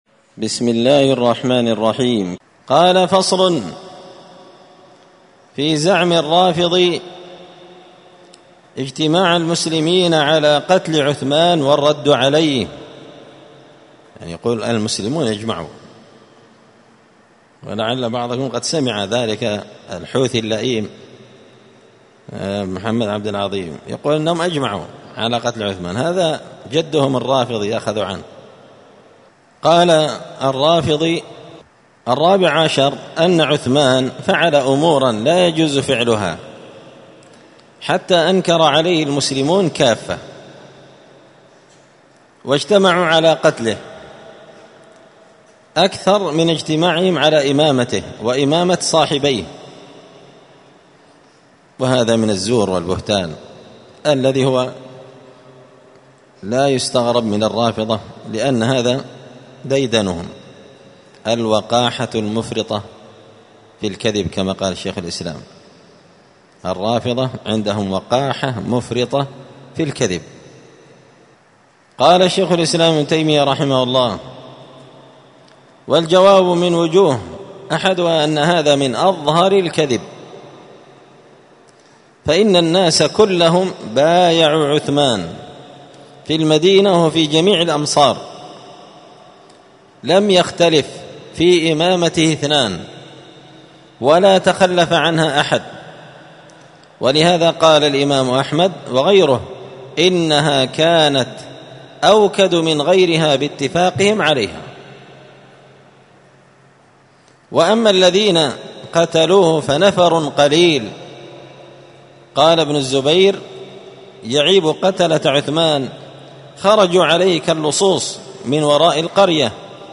*الدرس السادس والثلاثون بعد المائتين (236) فصل في زعم الرافضي اجتماع المسلمين على قتل عثمان والرد عليه*